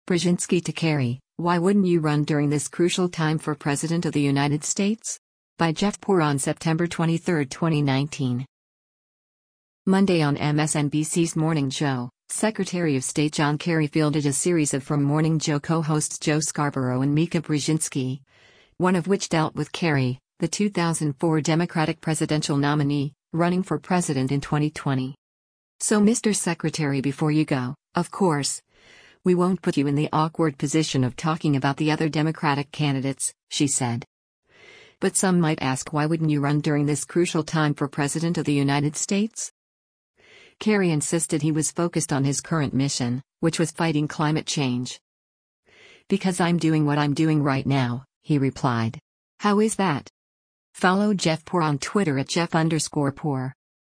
Monday on MSNBC’s “Morning Joe,” Secretary of State John Kerry fielded a series of from “Morning Joe” co-hosts Joe Scarborough and Mika Brzezinski, one of which dealt with Kerry, the 2004 Democratic presidential nominee, running for president in 2020.